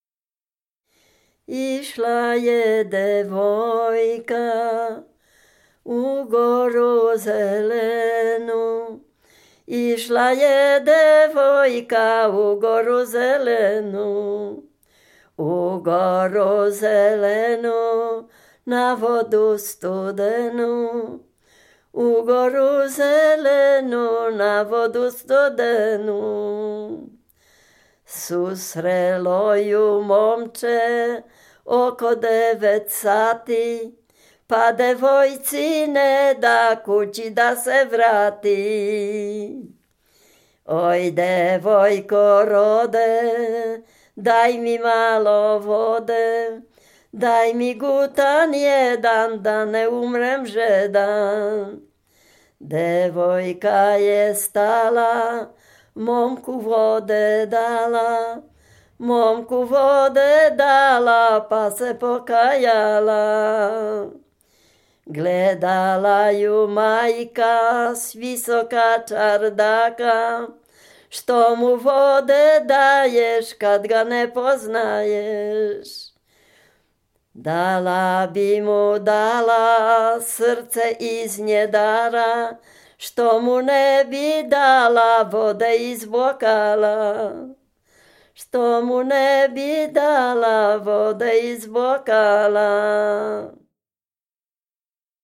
Dolny Śląsk, powiat bolesławiecki, gmina Nowogrodziec, wieś Zebrzydowa
liryczne miłosne